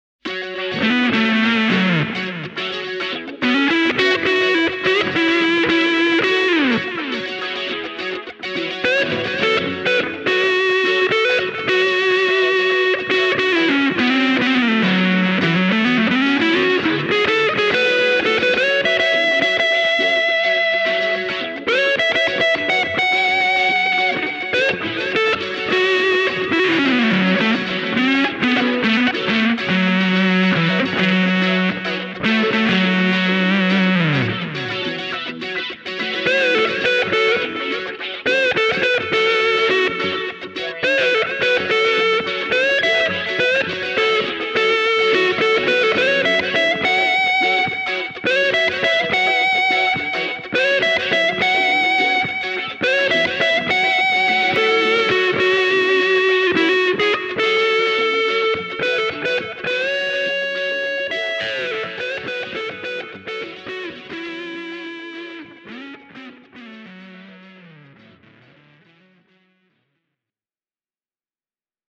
Demobiisissä on käytetty Electro-Harmonix Germanium 4 Big Muff Pi- ja Nano Big Muff Pi -säröjä Tokain ja Blackstar HT-1R -kombon lisäksi: